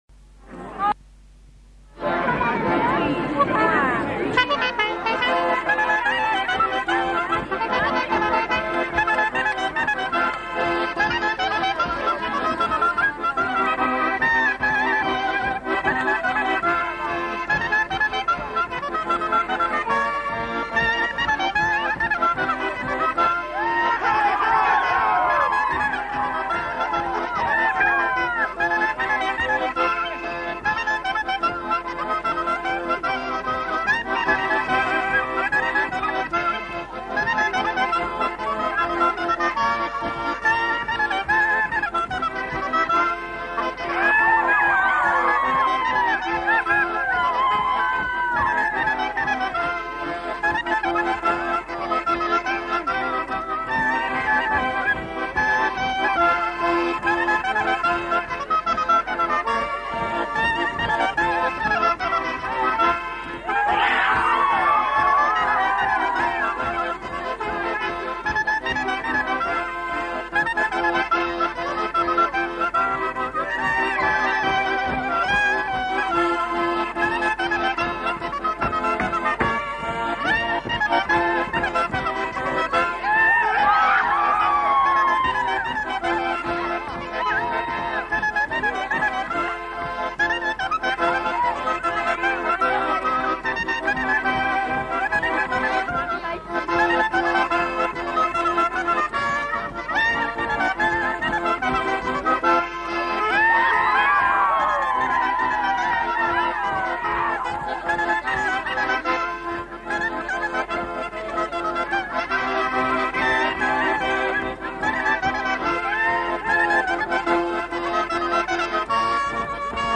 Instrumental. Accordéon chromatique, clarinette. Val Vermenagna
Aire culturelle : Val Vermenagna
Lieu : Limone
Genre : morceau instrumental
Instrument de musique : clarinette ; accordéon chromatique
Notes consultables : Les deux musiciens ne sont pas identifiés.